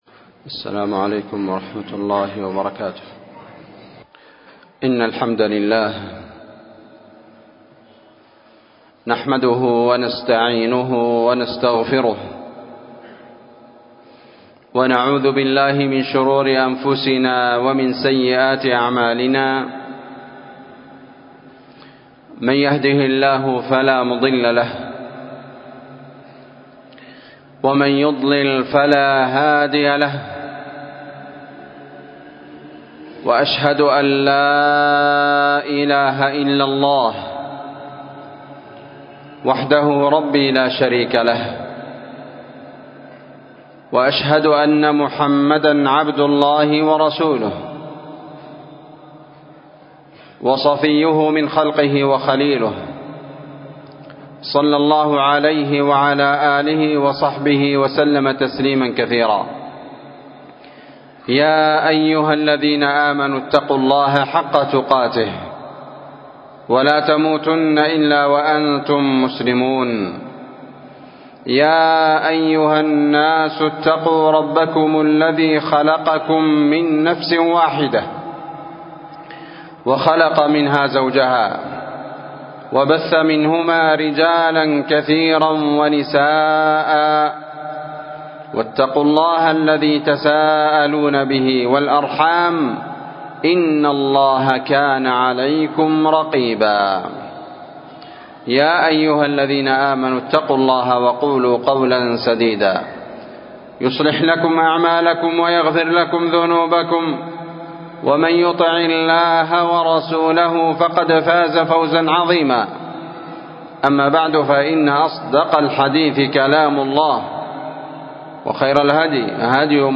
خطبة جمعة27محرم 1446هــ (الركون إلى الظالمين سبب العقوبة في الدنيا ويوم الدين)